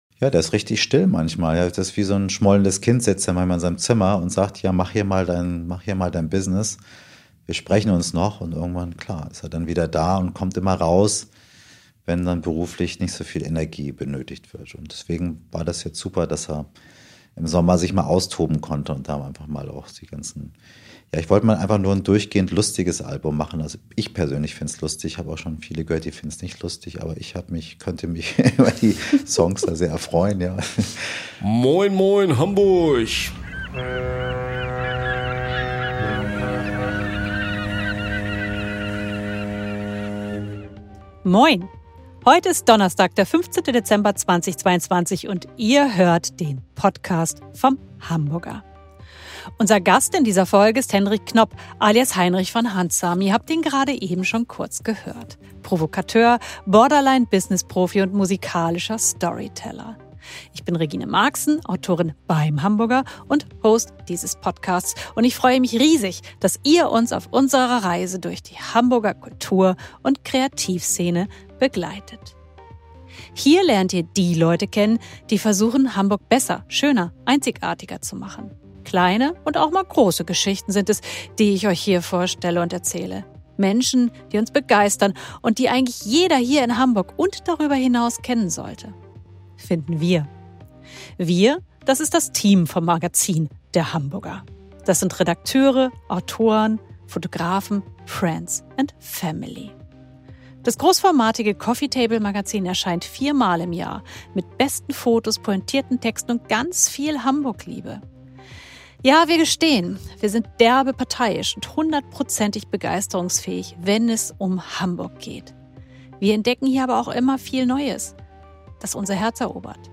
Ein Gespräch über Kunst, Kohle, Cannabis und die Musik als Ventil.